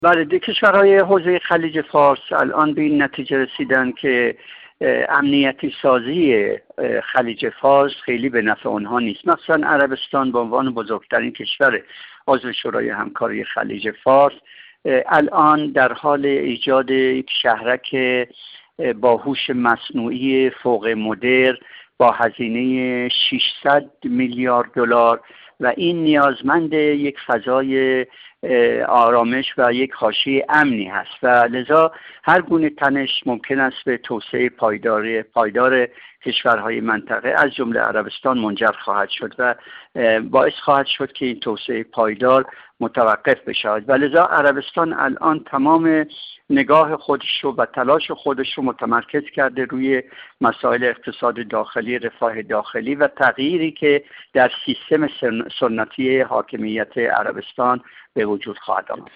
کارشناس مسائل منطقه
گفت‌وگو